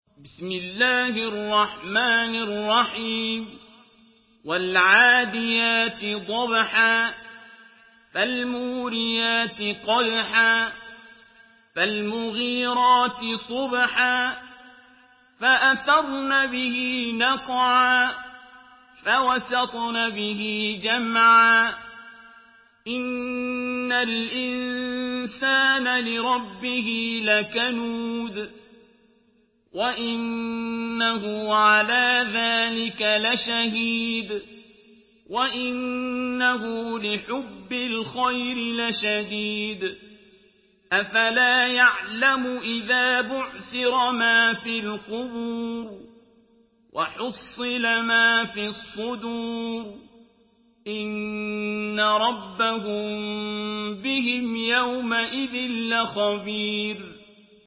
Surah Al-Adiyat MP3 by Abdul Basit Abd Alsamad in Hafs An Asim narration.
Murattal Hafs An Asim